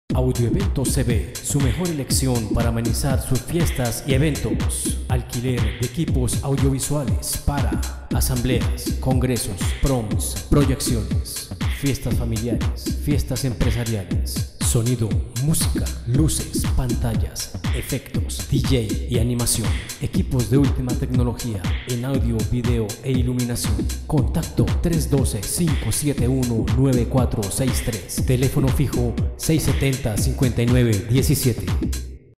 CUÑAS
Se promociona empresas pymes, en la capital, locusión Voz música de fondo.